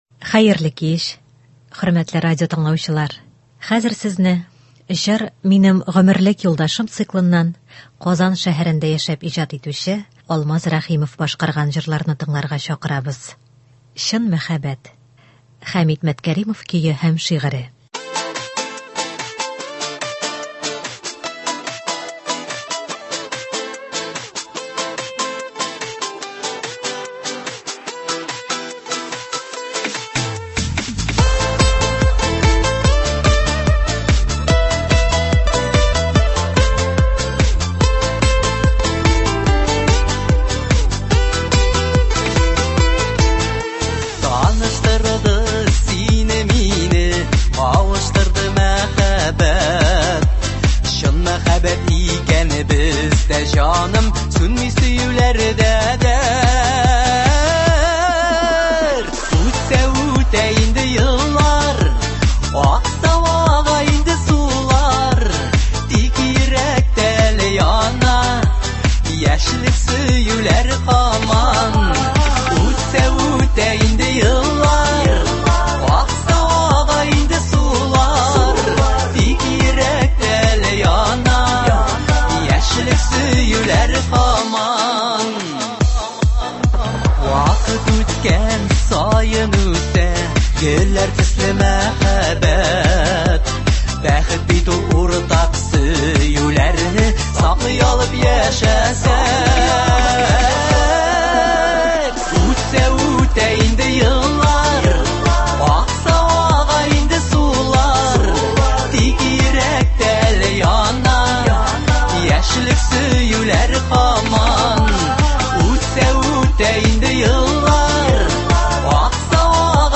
Концерт (09.01.23)